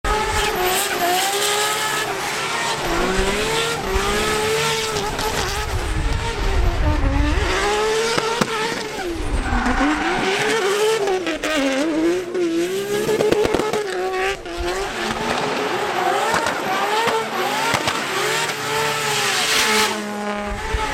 Best Sounding Car On The Sound Effects Free Download